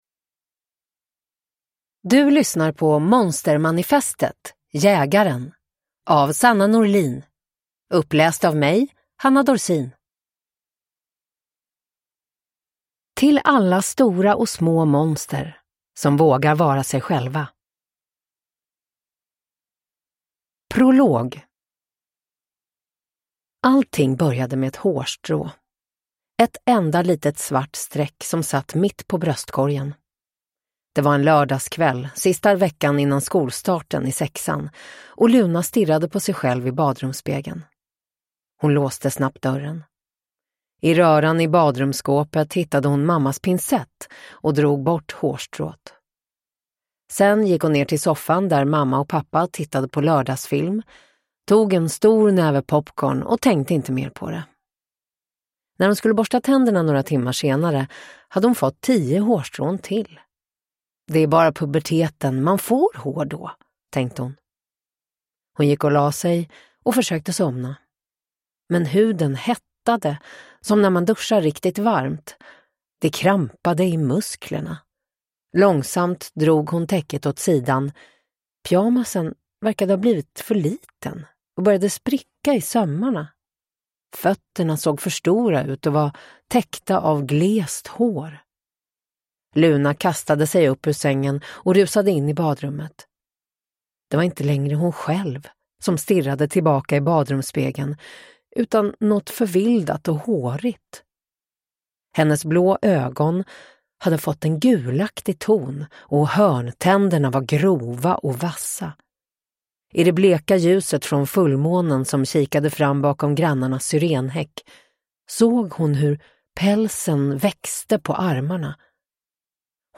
Uppläsare: Hanna Dorsin
Ljudbok